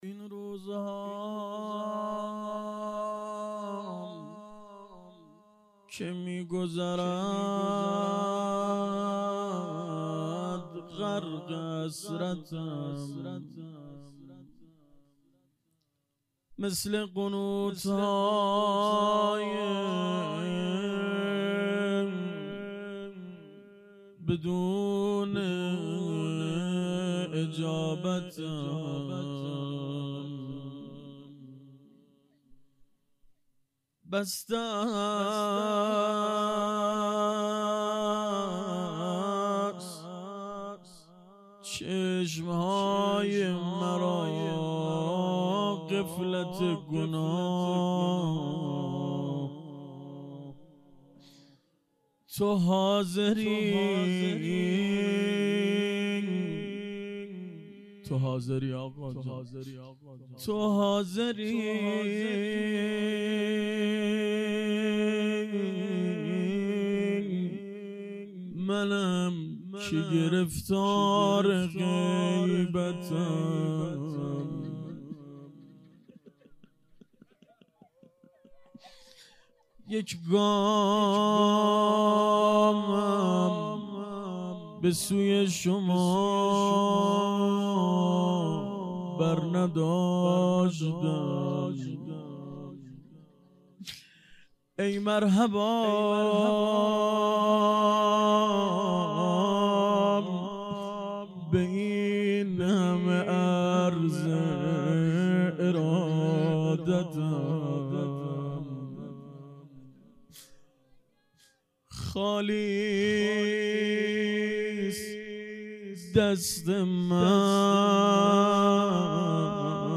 روضه شب چهارم